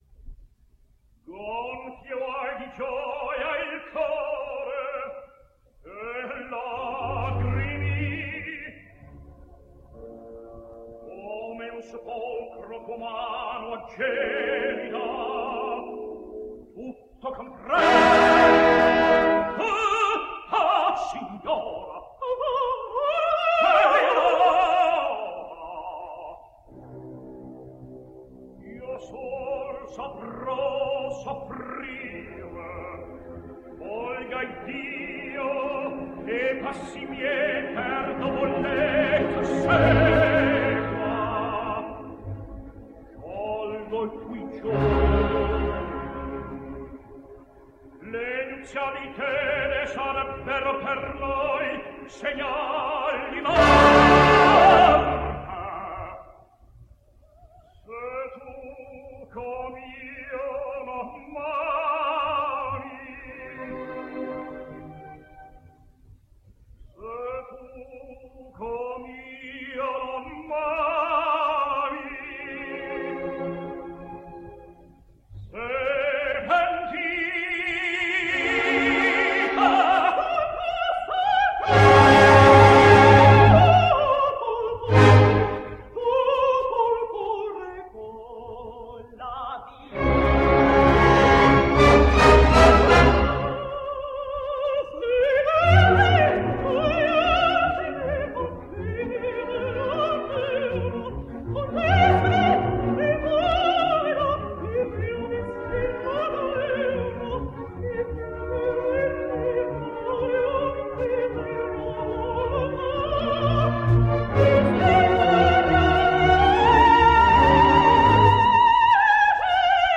American Tenor.
And then to conclude, a brisk jet of Price from the same set.